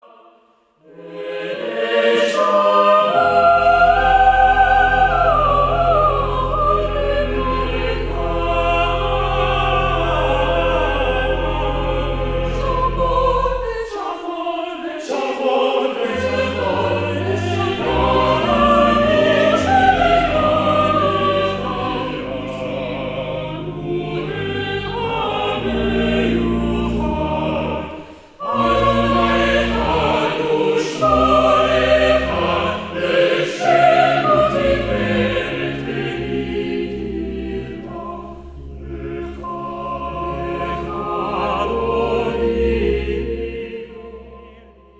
a choir and instruments
organist